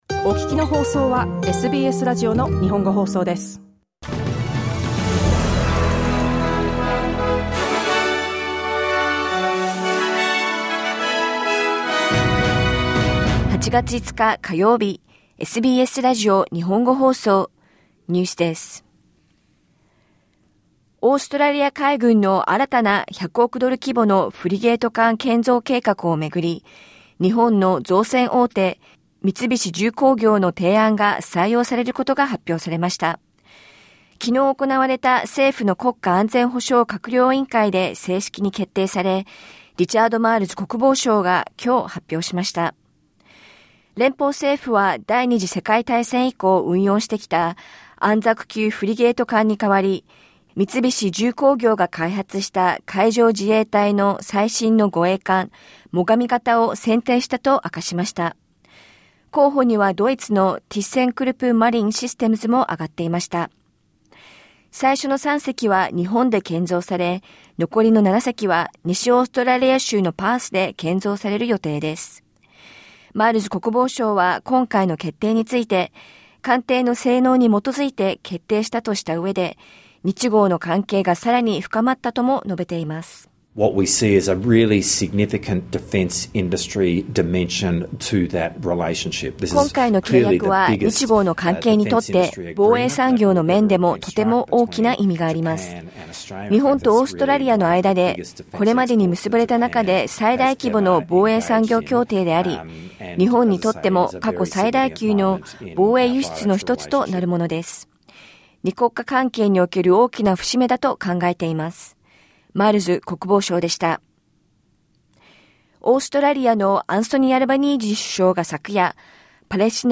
SBS日本語放送ニュース8月5日火曜日